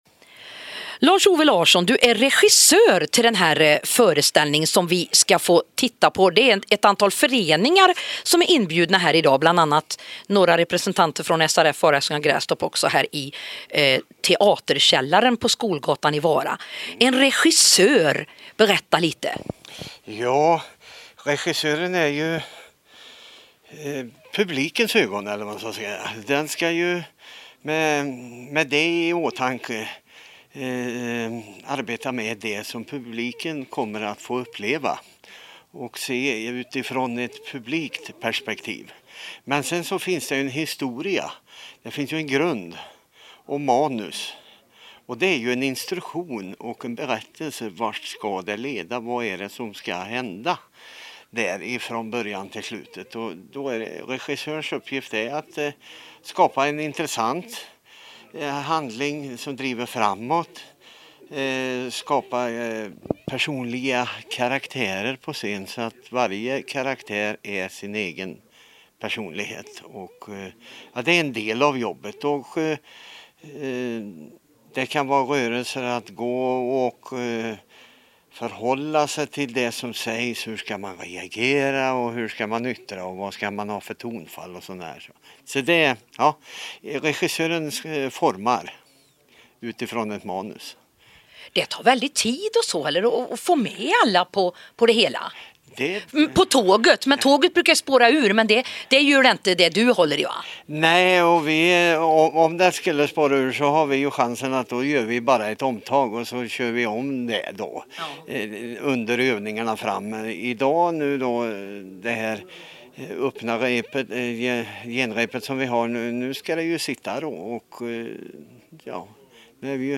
Teaterkällaren Vara
Vilken härlig intervju.